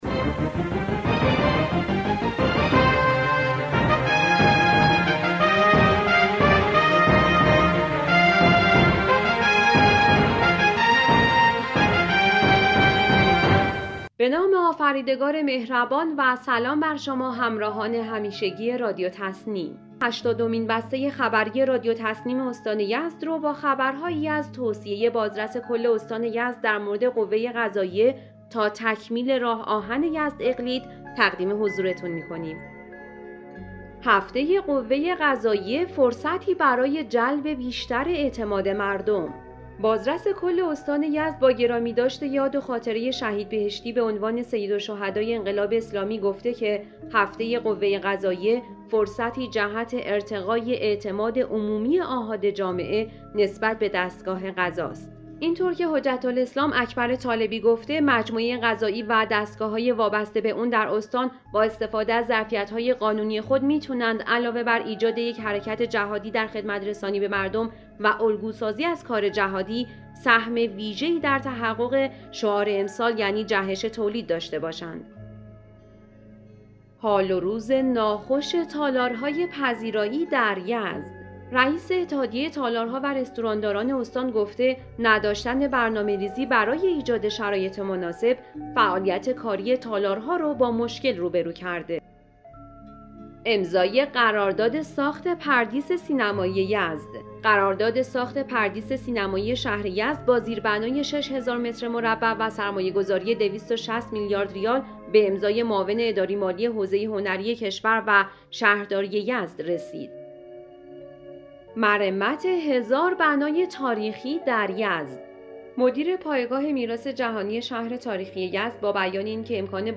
به گزارش خبرگزاری تسنیم از یزد, هشتادمین بسته خبری رادیو تسنیم استان یزد با خبرهایی از توصیه بازرس کل استان درمورد هفته قوه قضائیه, حال و روز ناخوش تالارهای پذیرایی, امضای قرارداد ساخت پردیس سینمایی یزد, مرمت 1000 بنای تاریخی, بانوان بهترین مروجین فرهنگ اهدای خون در خانواده و اختصاص 3 هزار میلیارد ریال اعتبار برای تکمیل راه آهن یزد - اقلید منتشر شد.